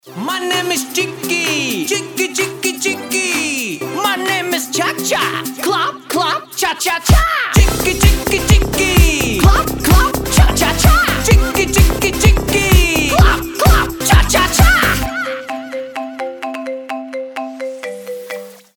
• Качество: 320, Stereo
позитивные
зажигательные
веселые
ча-ча-ча